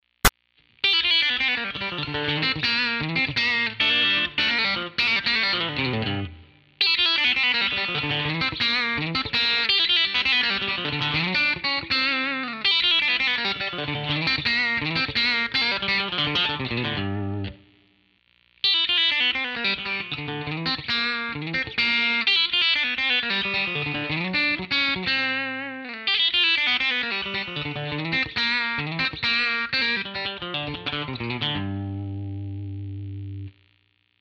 It is one note at a time.
I did this in one take, so there are a few bad spots.
actually sound ok, all the notes are there,